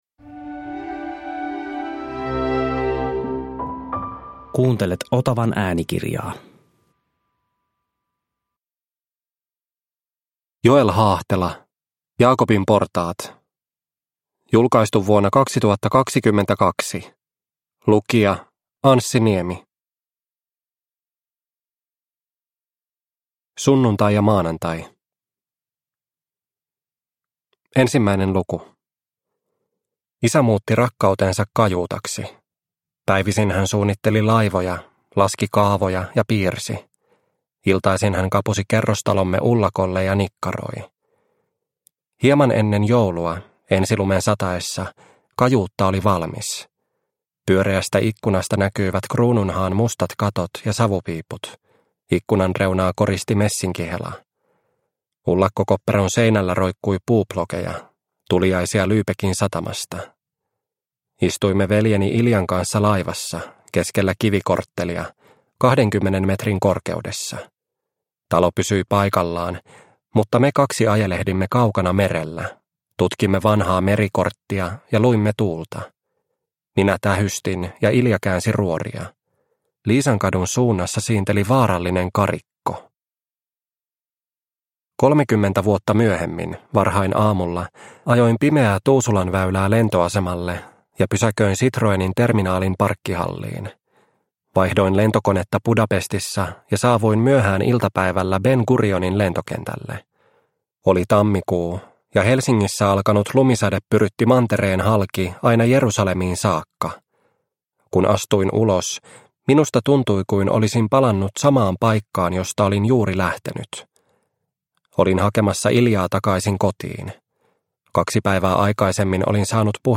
Jaakobin portaat – Ljudbok – Laddas ner